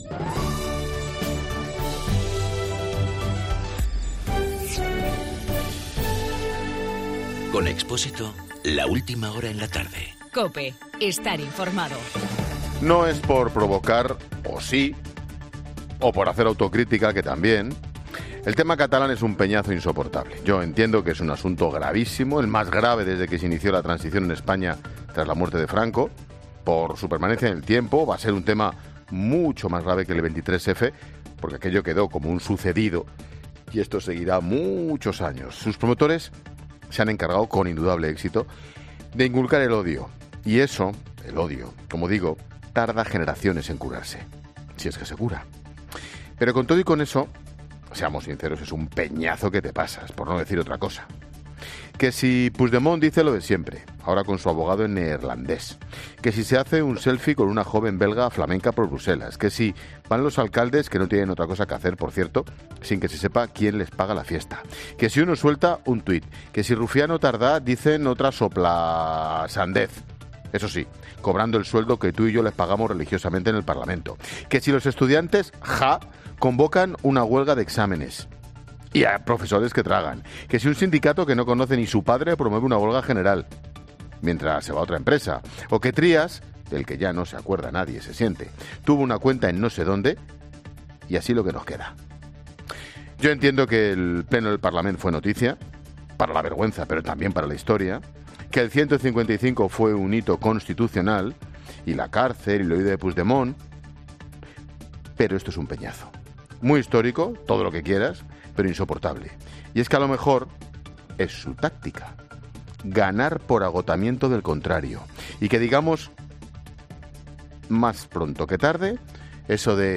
AUDIO: El comentario de Ángel Expósito.
Monólogo de Expósito